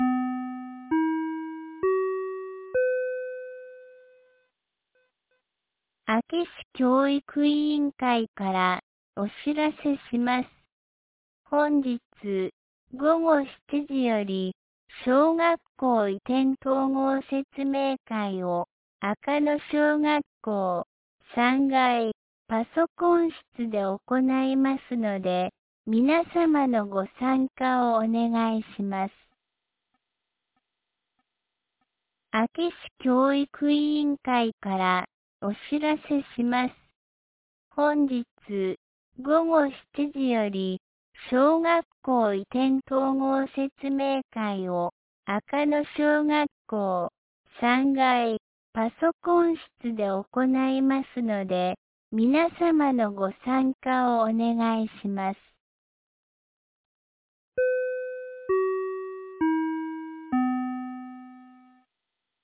2026年02月05日 17時21分に、安芸市より赤野へ放送がありました。